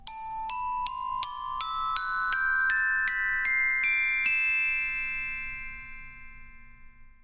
Звуки ксилофона
Звук ксилофона поднимается вверх